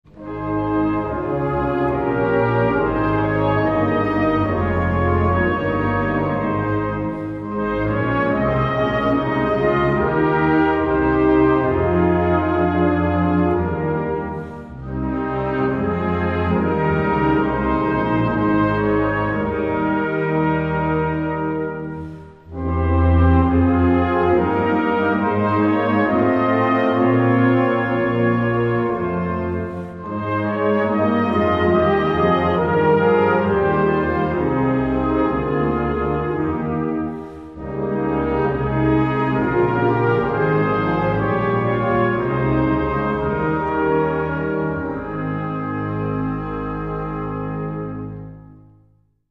Concert Band ou Harmonie ou Fanfare ou Brass Band